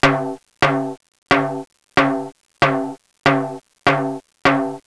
Timbalesslow
TimbalesSlow.wav